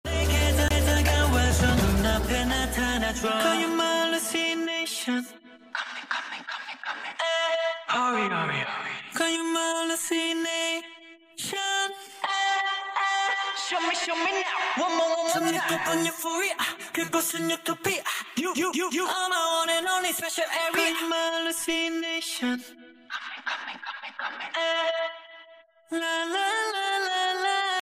but only his voice